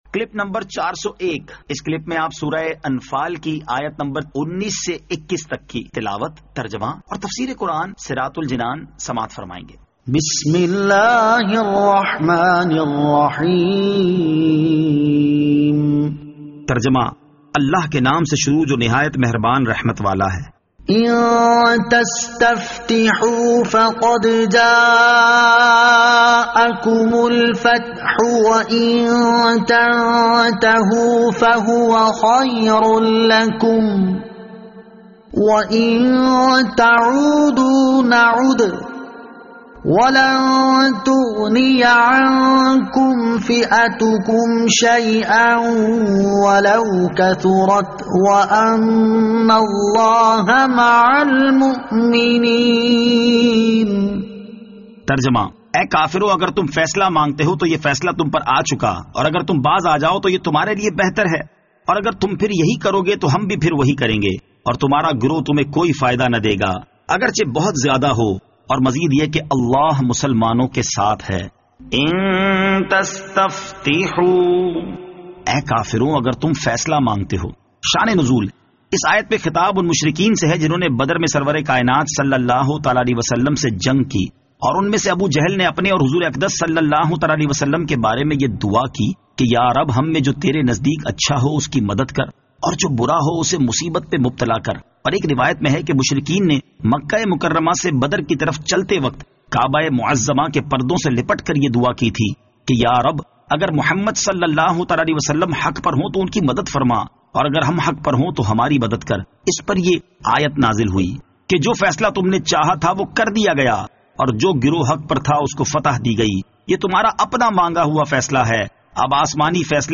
Surah Al-Anfal Ayat 19 To 21 Tilawat , Tarjama , Tafseer